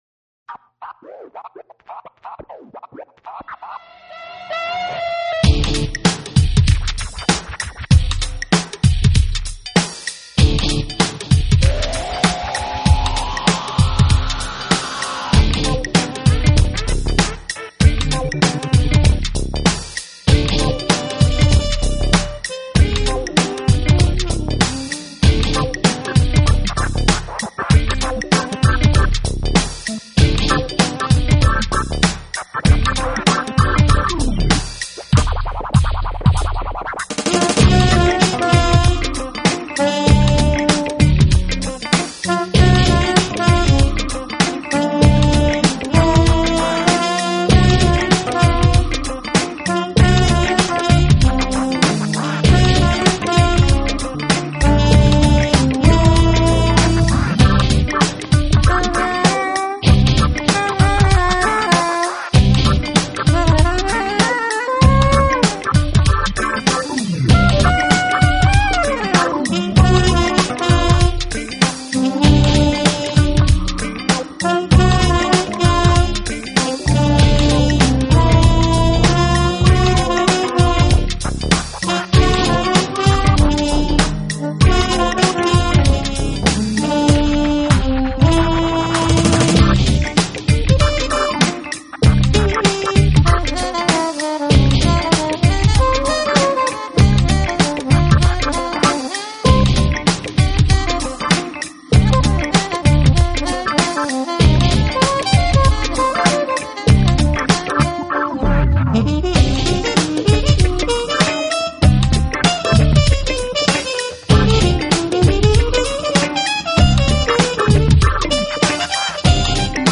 风格：Jazz+funk, Crossover Jazz